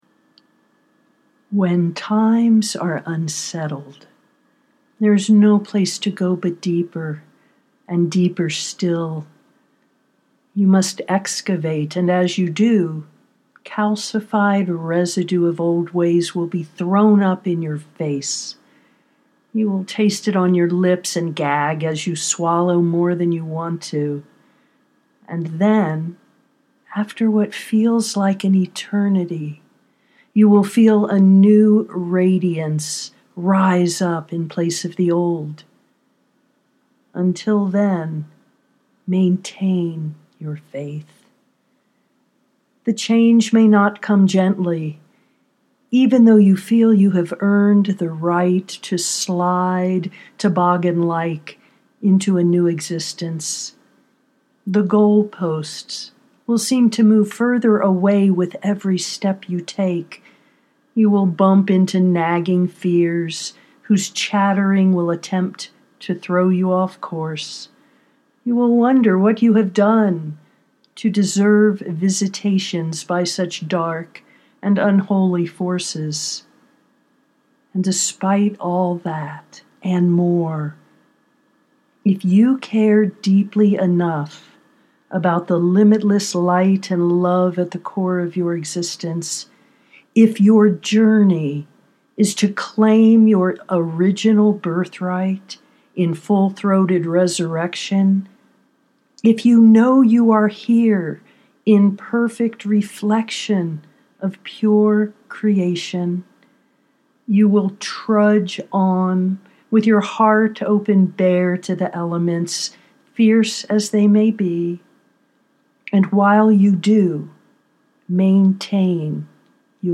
until then, maintain your faith (audio poetry 4:36)